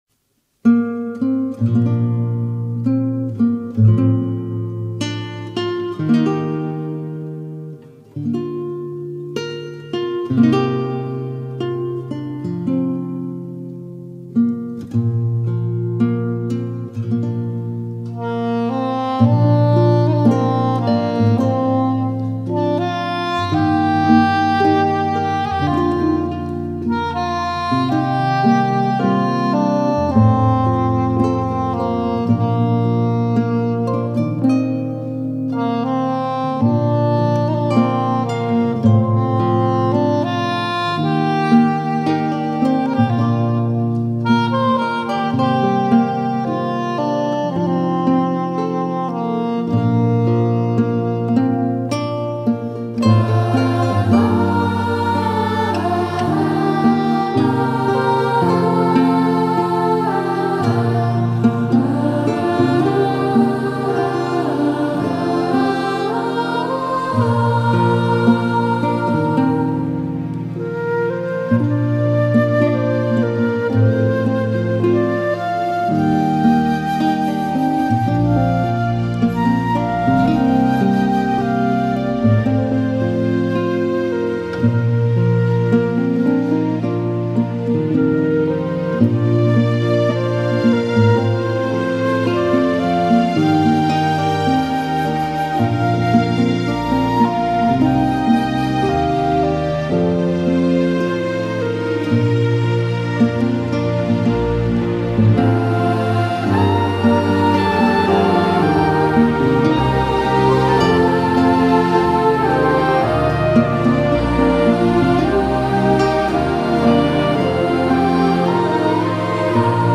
Logroño 27 y 28 de enero de 2024.
Como siempre el Foro se inicia y se acaba con la Danza de Bendición: mil doscientas personas siguiendo la melodía de pie, sin romper el silencio, y ejecutando la sencilla coreografía que expresa nuestra unidad con la Tierra, con las personas allí presentes, con toda la humanidad y con nuestro propio ser.